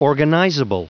Prononciation du mot organizable en anglais (fichier audio)
Prononciation du mot : organizable